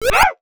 BounceToad.wav